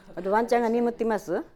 Type: Yes/no question
Final intonation: Rising
Location: Showamura/昭和村
Sex: Female